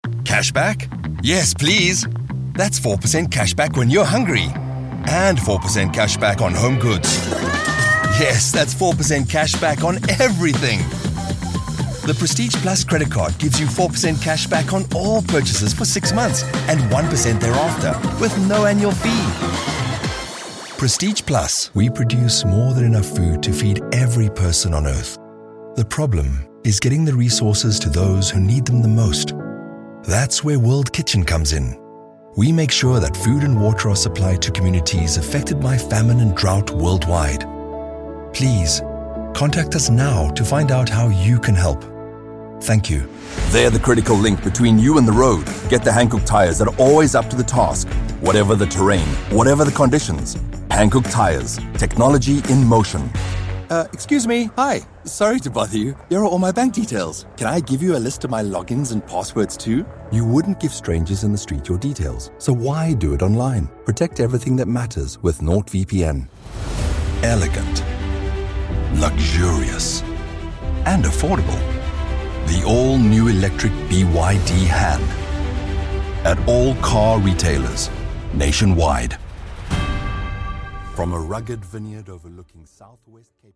Englisch (südafrikanisch)
Ich bin ein freundlicher, vielseitiger und professioneller südafrikanischer Synchronsprecher, der Wert auf Verbindungen durch Geschichten legt ...
Vertrauenswürdig
Autorisierend
Warm